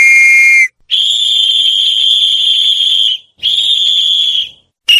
Running Fast is a free foley sound effect available for download in MP3 format.
# running # fast # footsteps About this sound Running Fast is a free foley sound effect available for download in MP3 format.
329_running_fast.mp3